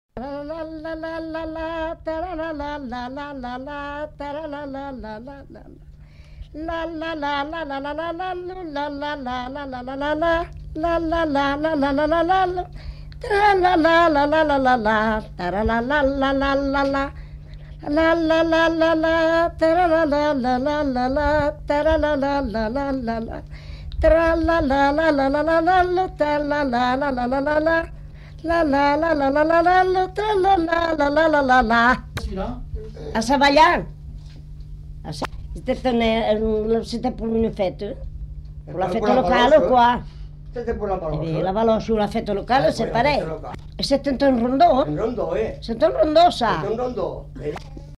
Lieu : [sans lieu] ; Gers
Genre : chant
Effectif : 1
Type de voix : voix de femme
Production du son : fredonné
Danse : rondeau